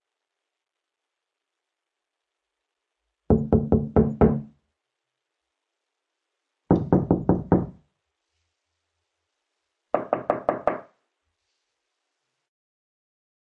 SFX 木制物品 " 木头敲击声2
描述：敲打着木头。用Rode NT4在Zoom H4中进行立体声（XY）录制。
标签： 广场 木材 木板
声道立体声